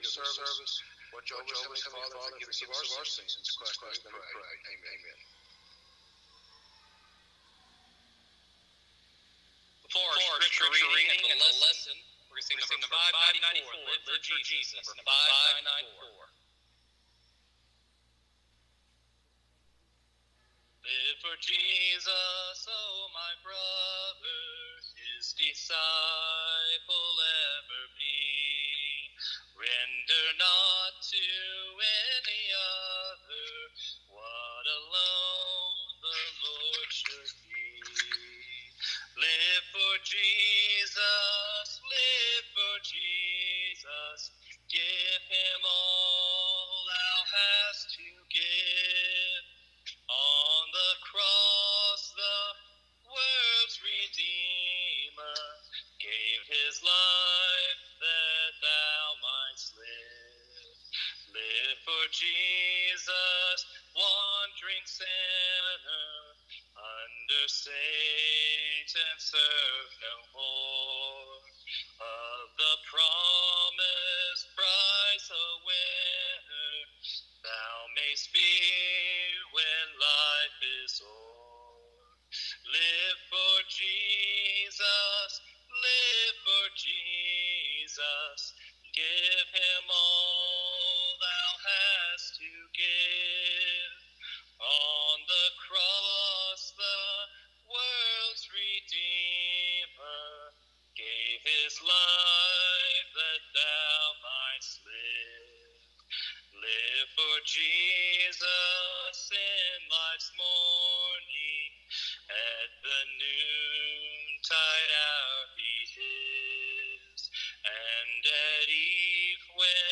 Luke 19:10, English Standard Version Series: Sunday PM Service